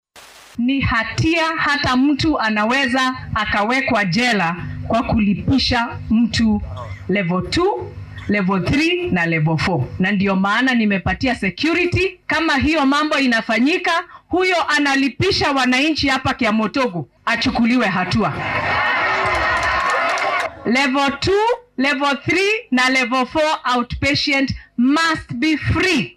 Xoghayaha Joogtada ah ee wasaaradda caafimaadka dalka ,Mary Muthoni ayaa digniin adag u dirtay maamullada isbitaallada ee isku daya in ay dhaqaale ahaan ka macaashaan dadka iska diiwaangeliyay caymiska caafimaadka bulshada ee SHA. Waxay madal dadweyne oo lagu qabtay ismaamulka Kirinyaga ka sheegtay in shakhsiyaadkan ay halis ugu jiraan xarig iyo dacwad lagu soo oogo. Mary Muthoni ayaa xustay in dowladdu ay tallaabo adag ka qaadeysa saraakiisha ka howlgalo isbitaallada heerarka 2, 3, iyo 4-aad ee si sharci darro ah lacago uga qaado bukaannada ka diiwaangashan caymiska SHA.